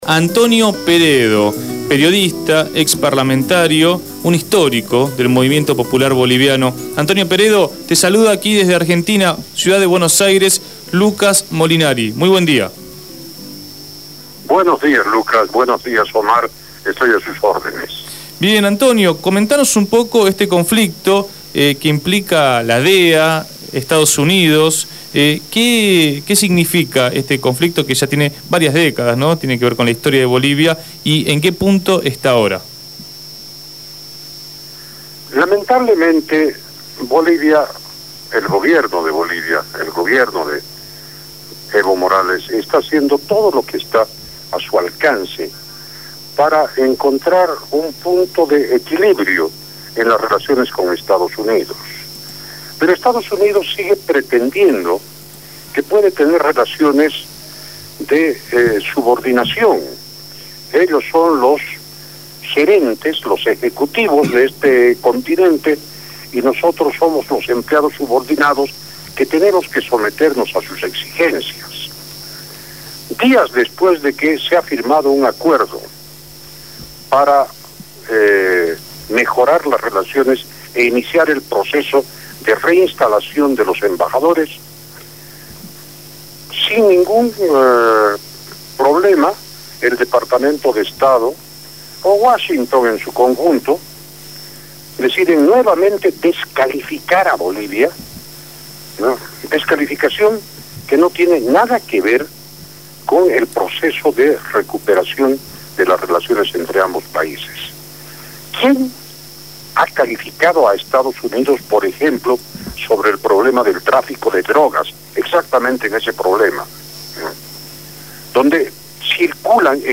Antonio Peredo, periodista y ex senador boliviano por el Movimiento al Socialismo (MAS), habló en Punto de Partida.